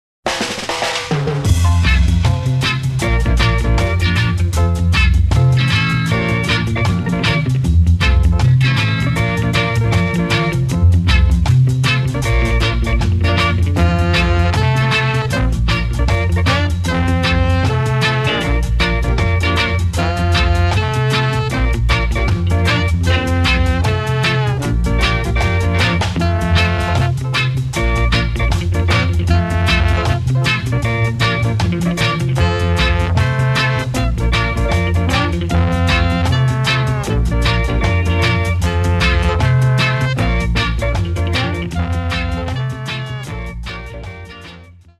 An instrumental cut